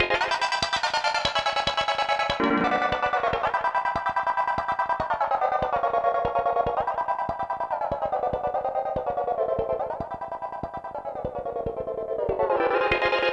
synthFX01.wav